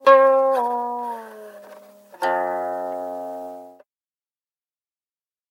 Guqin.ogg